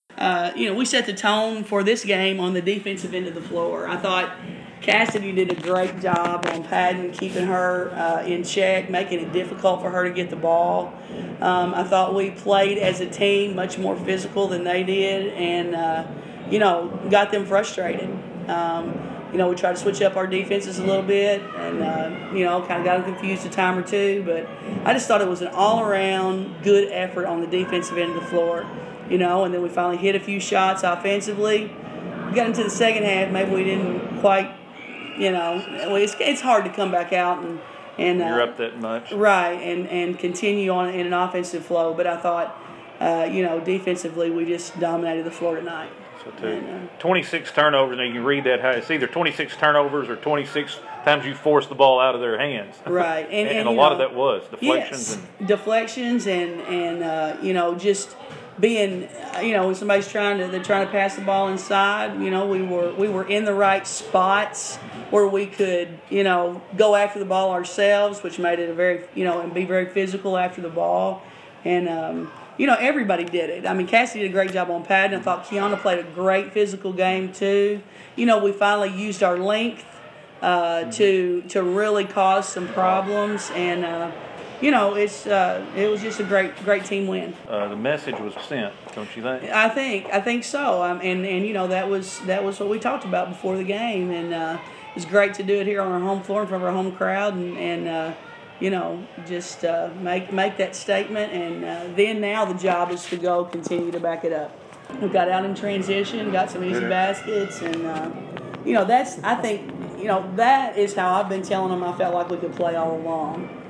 CCHS coaches discuss Livingston Games and beyond